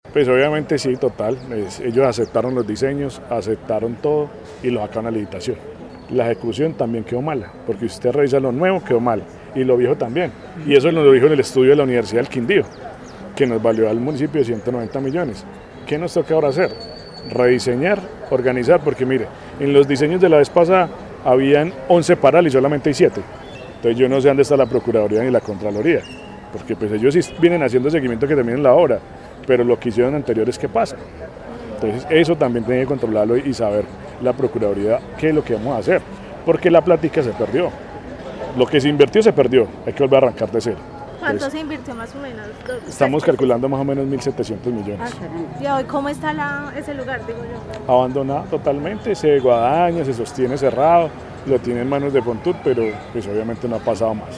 Alcalde de Montenegro